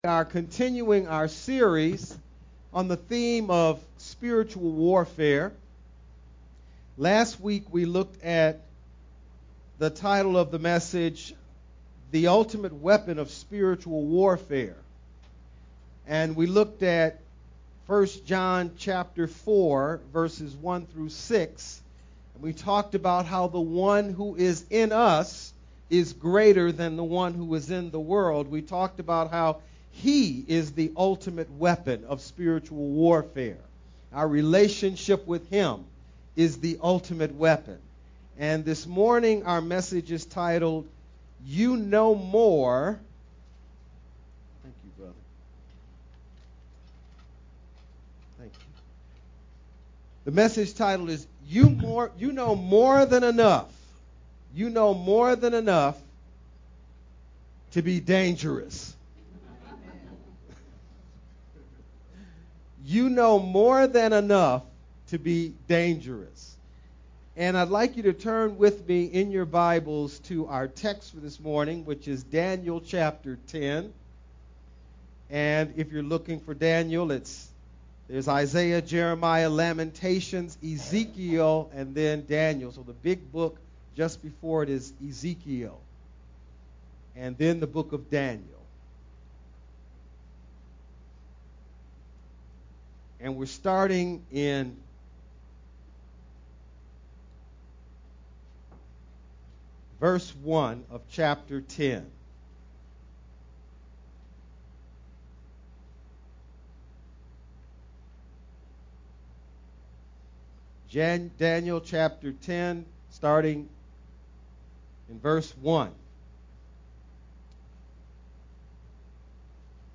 Daniel 10: 1-14 Main idea: Hold on to what you already know and it will guide you through the unknown. Message